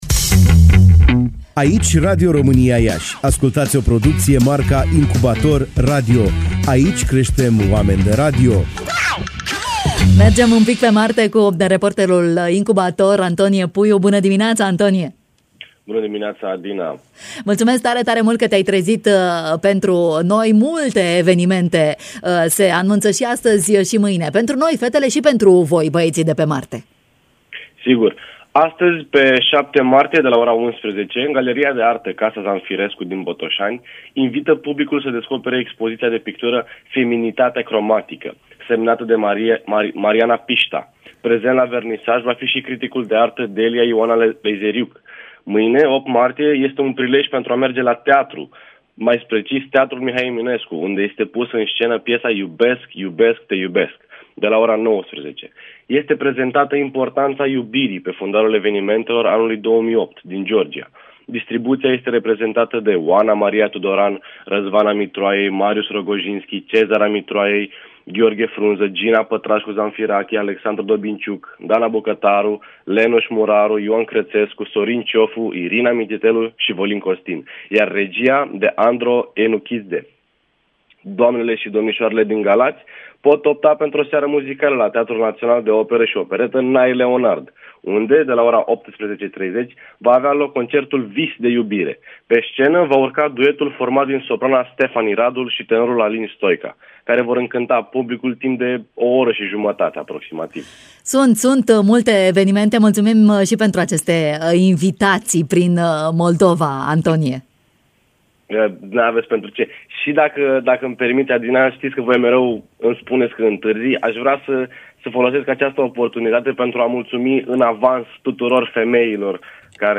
în direct la Bună Dimineața